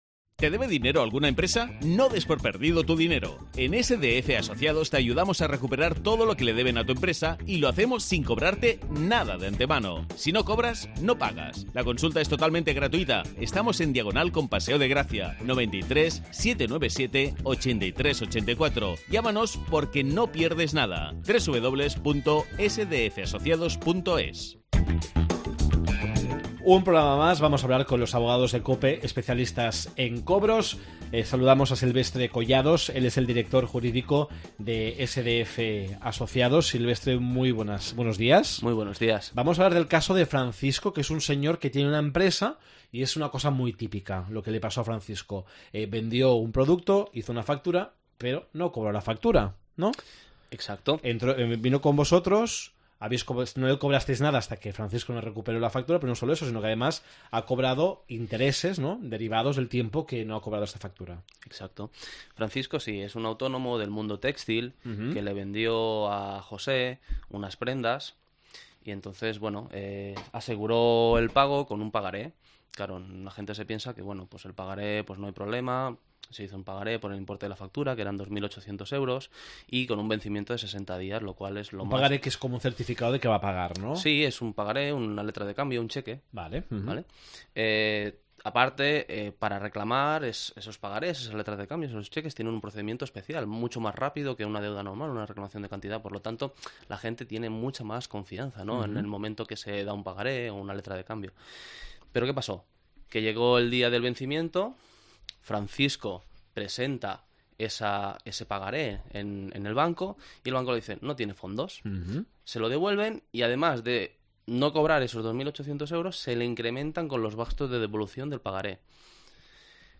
Duros a cuatre Duros a 4 pts Com podem recuperar els deutes? Entrevista